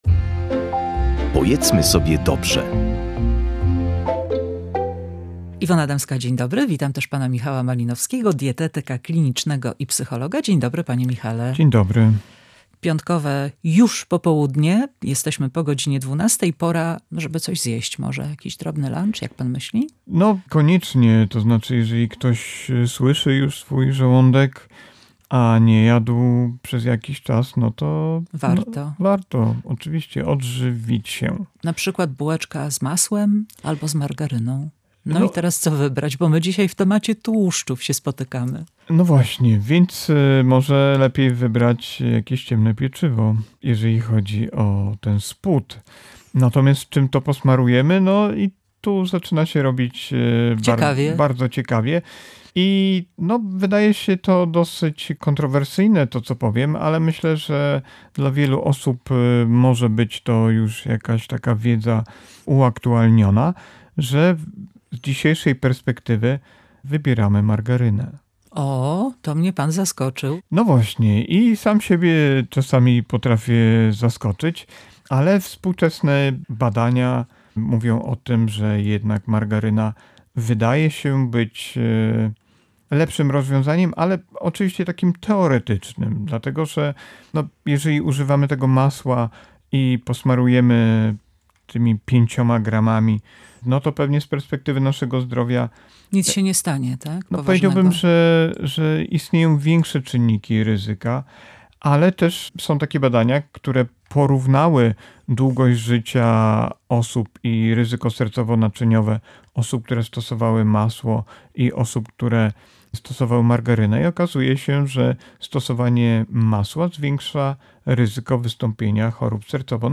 Wyjaśnia dietetyk